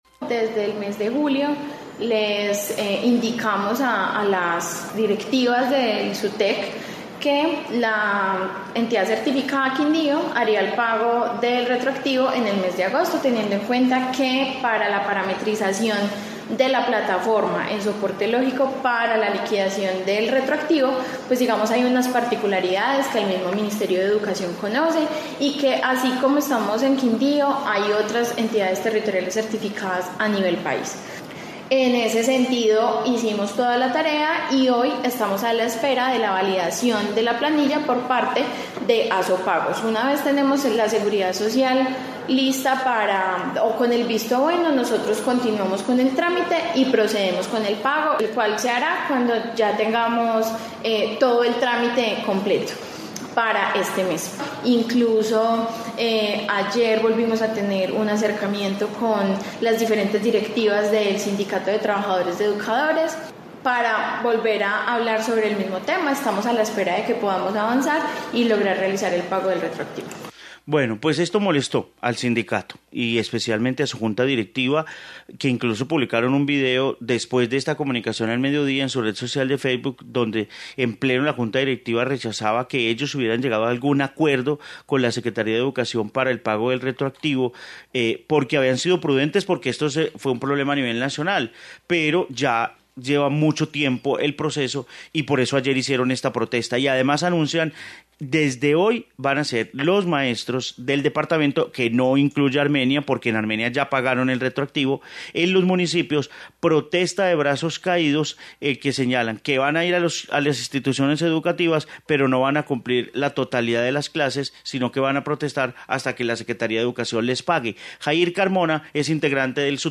Informe protesta docentes Quindío